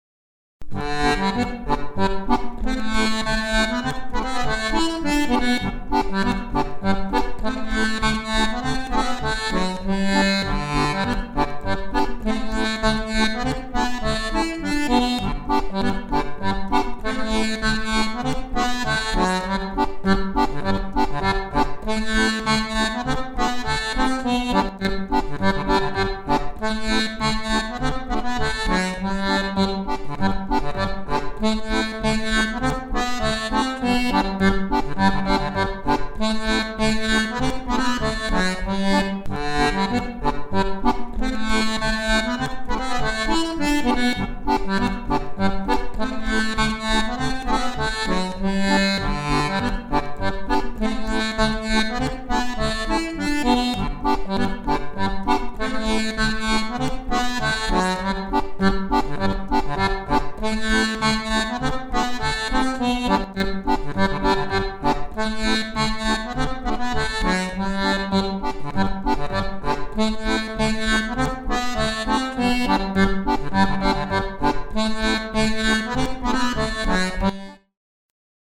Tabs for chromatic accordion
La semaine des 7 lundis* Scottish à 2 voix Voix 1
Voix 2